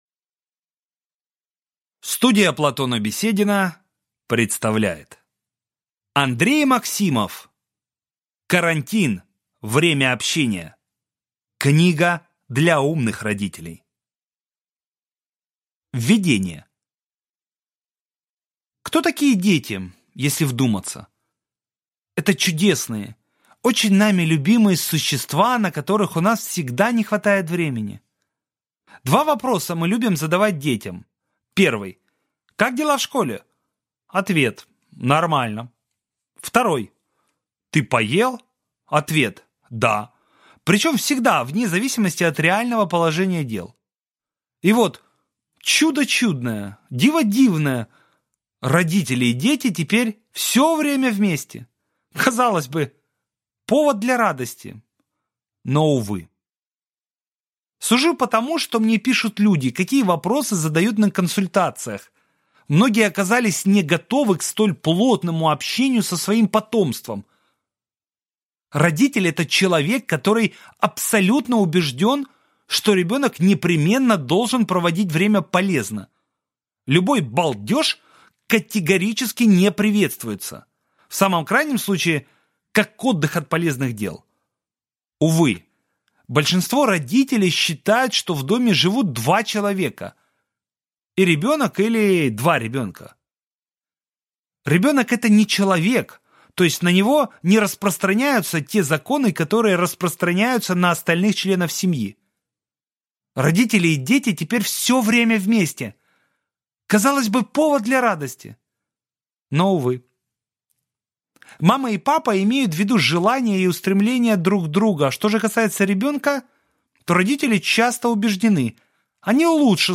Аудиокнига Обойдемся без педагогики даже на карантине. Книга для умных родителей | Библиотека аудиокниг